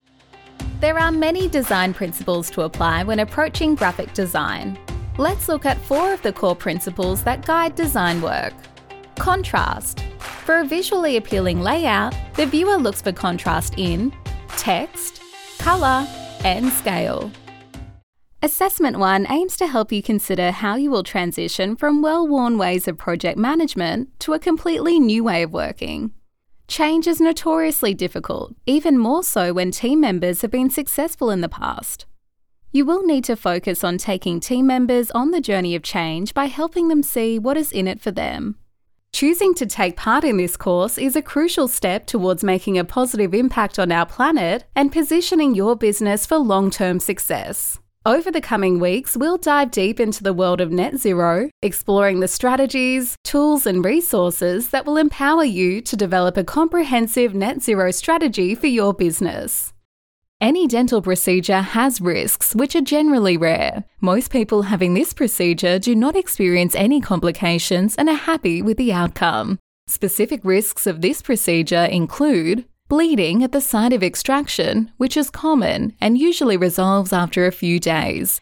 Australian female voiceover artist, with a voice often described as:
Recording from my sound engineer approved home recording studio
E-Learning
Friendly, Knowledgeable